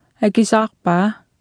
Below you can try out the text-to-speech system Martha.